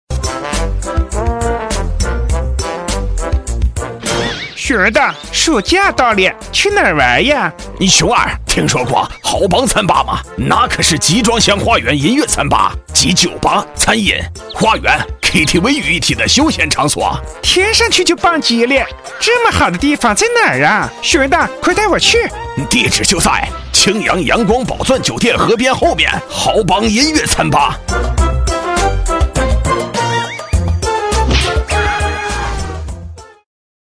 【男5号模仿】熊二 熊大，暑假到了
【男5号模仿】熊二  熊大，暑假到了.mp3